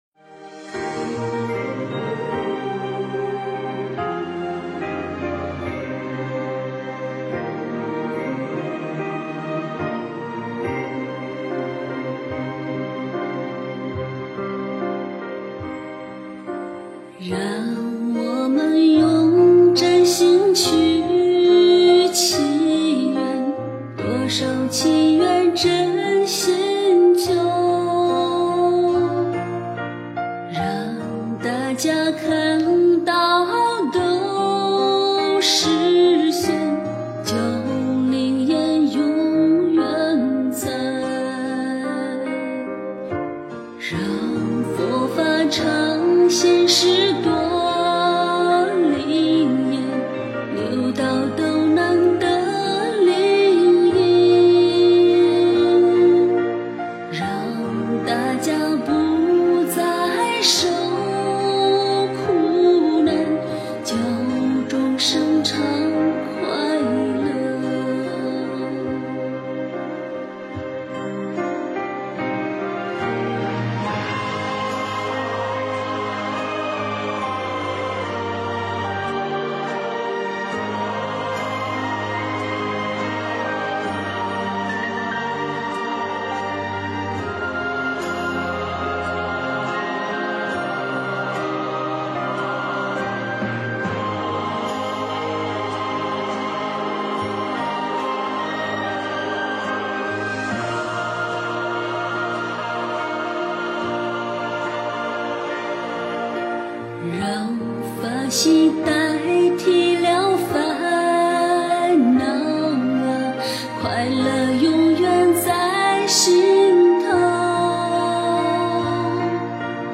起愿 诵经 起愿--佛教音乐 点我： 标签: 佛音 诵经 佛教音乐 返回列表 上一篇： 念着佛的名字入寂 下一篇： 人人有颗难管的心 相关文章 《妙法莲华经》如来神力品第二十一--佚名 《妙法莲华经》如来神力品第二十一--佚名...